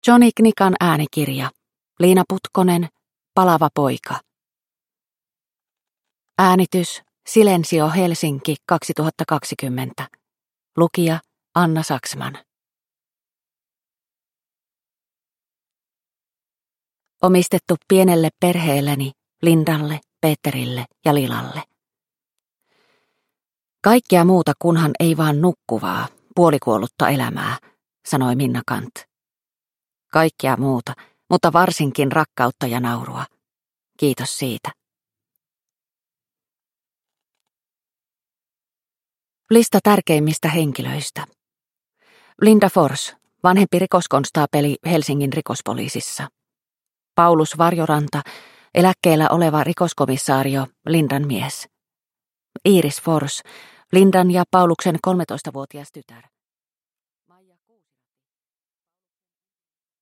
Palava poika – Ljudbok – Laddas ner